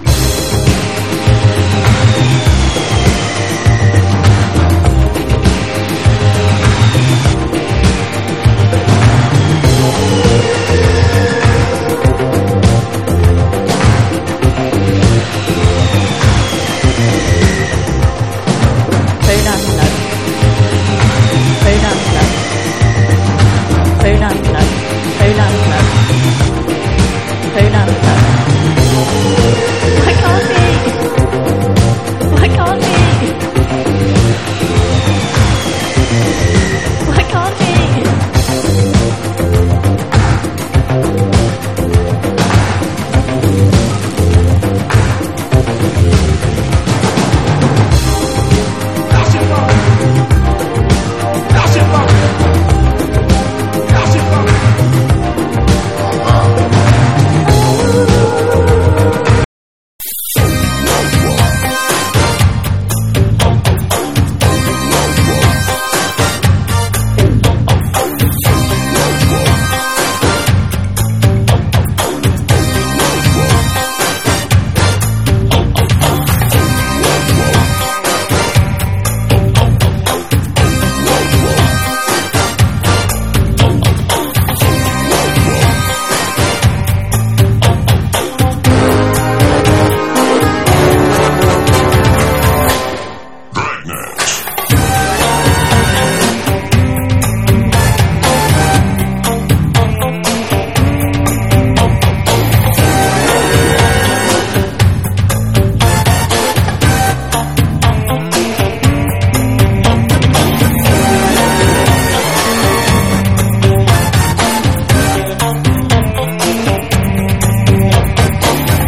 ¥1,180 (税込) ROCK / 80'S/NEW WAVE.